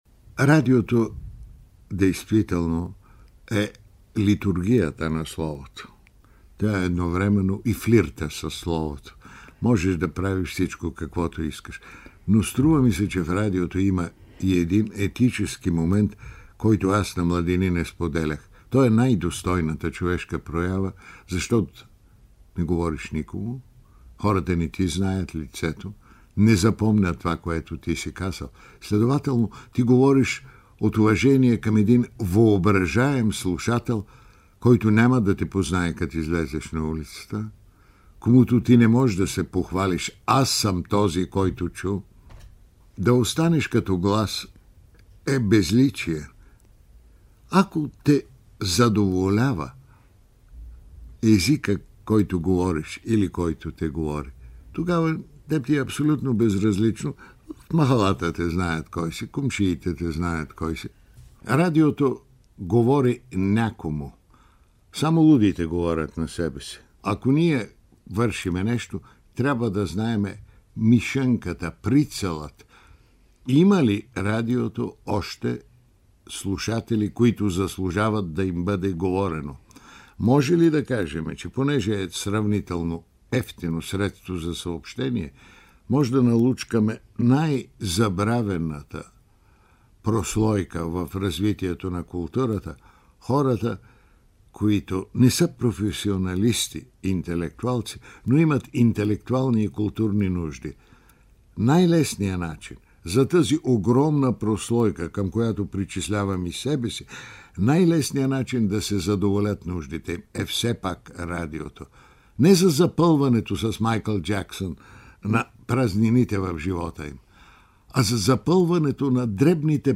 Неговите размисли и наблюдения на световните тенденции в развитието на радиопрограмите и влиянието на радиото продължават в интервю за БНР през 1993 година: „За да разберем силата на радиото, трябва да видим какво стана в Германия след Втората световна война, когато всичко беше тотално разрушено.